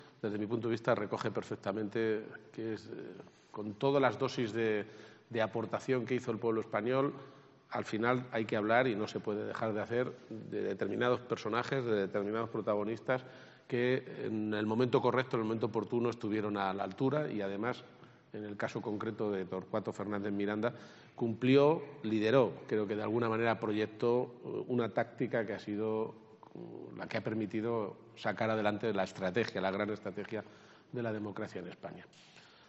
6:15pm El presidente de Castilla-La Mancha ha asistido a la presentación del libro ‘Objetivo Democracia’